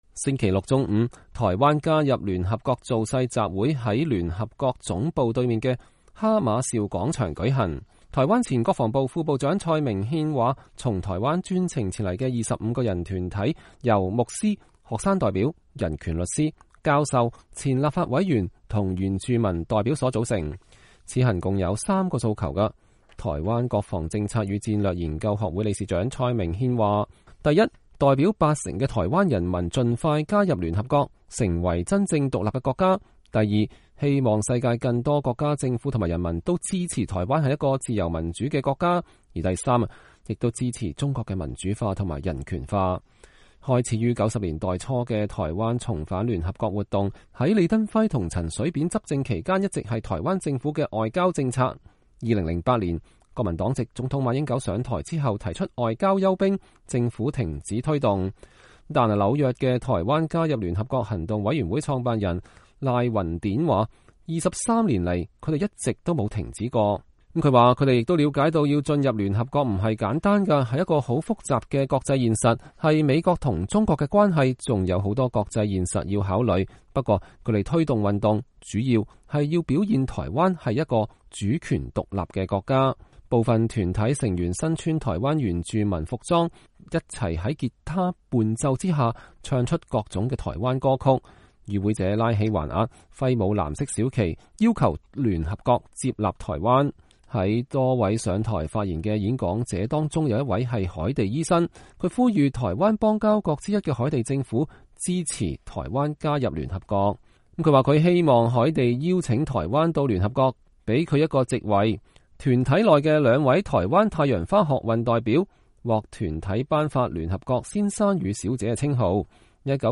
星期六中午，台灣加入聯合國造勢集會在聯合國總部對面的哈馬紹廣場舉行。
部份團體成員身穿台灣原住民服裝；大家在結他伴奏下唱各種台灣歌曲。